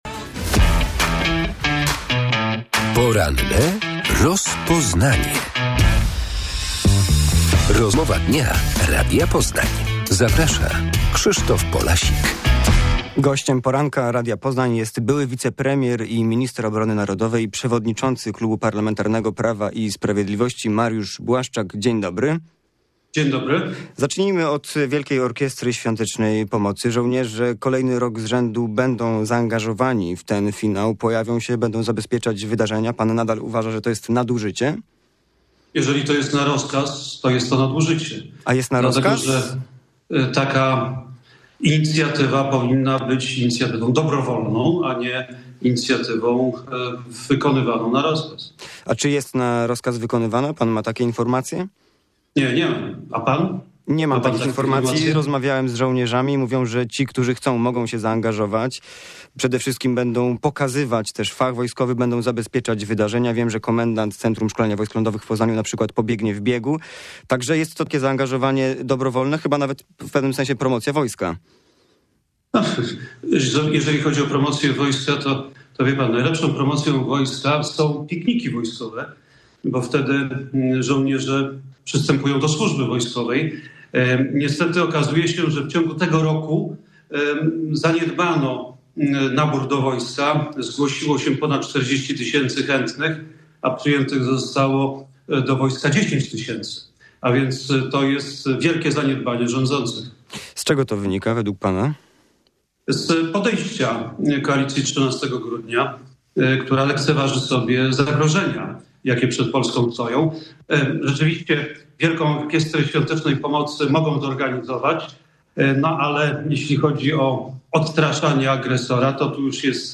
W porannej rozmowie o wypłacie pieniędzy dla PiS za kampanię wyborczą w 2023 roku i przyszłości projektu Pancerna Wielkopolska. Gościem programu jest przewodniczący klubu parlamentarnego Prawa i Sprawiedliwości Mariusz Błaszczak